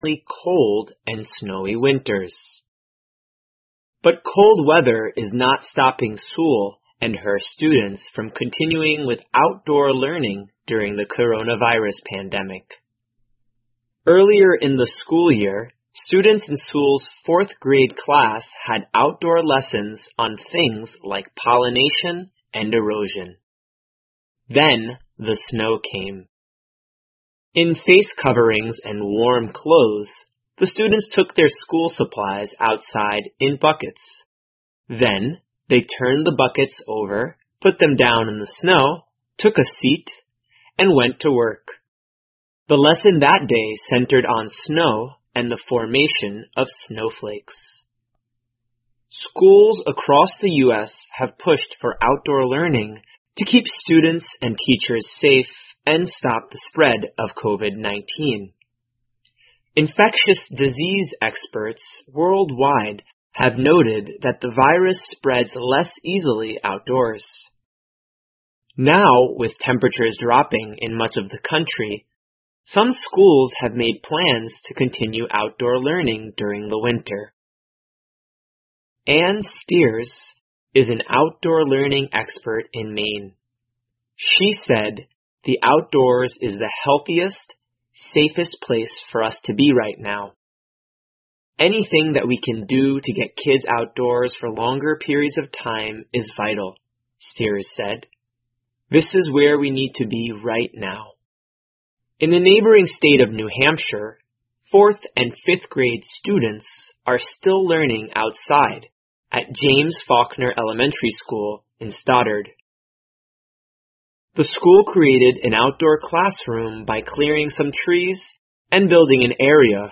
VOA慢速英语2021--户外教育在寒冷中继续进行 听力文件下载—在线英语听力室